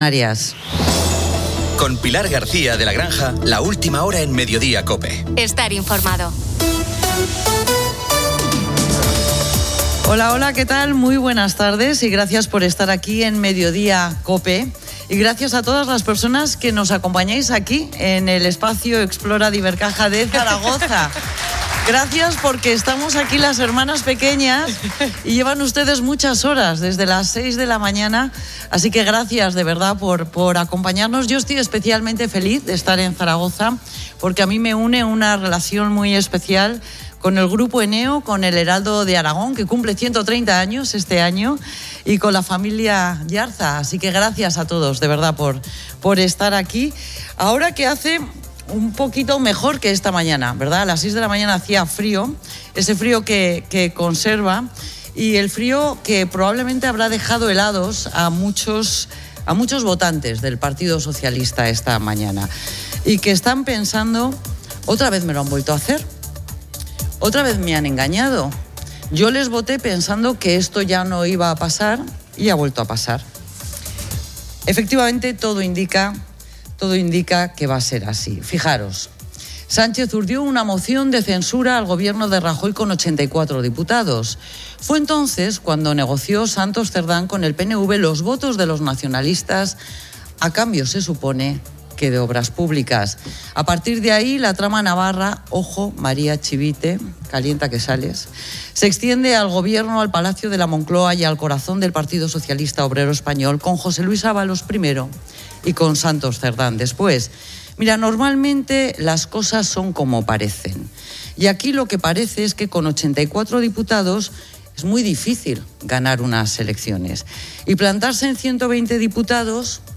Pilar García de la Granja presenta la actualidad en COPE, donde Santos Cerdán está a punto de ser liberado de prisión después de cinco meses, ya que el juez considera mitigado el riesgo de destrucción de pruebas. El último informe de la UCO detalla presuntas comisiones del 2% en obras públicas a través de la empresa Servinavar. Feijóo critica a Sánchez en el Congreso por la corrupción, calificando la situación de España de "cloaca".